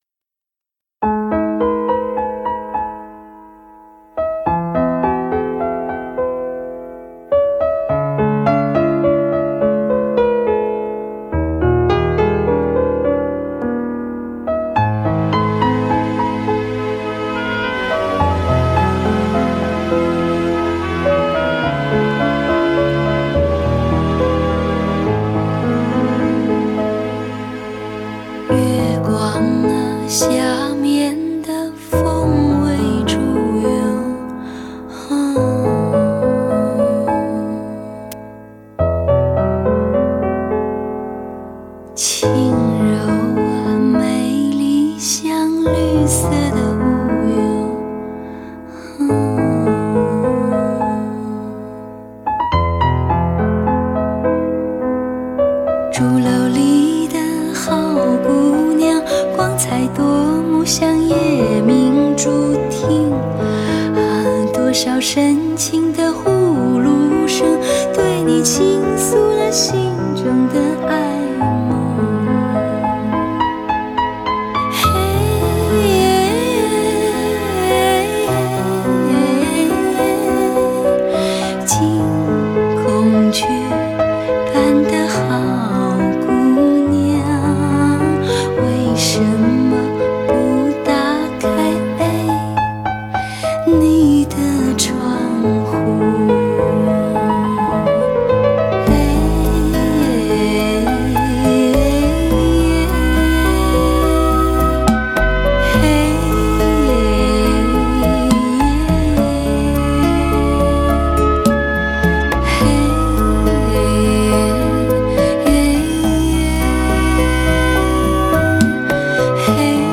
诚恳真挚的声音，加上纯净无暇的音符，绝对能够触动您的心弦。